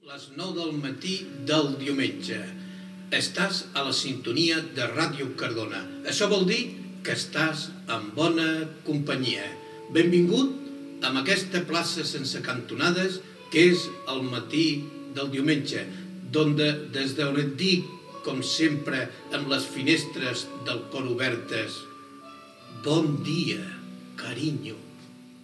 Hora, identificació i presentació del programa del matí del diumenge.
FM